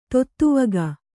♪ tottuvaga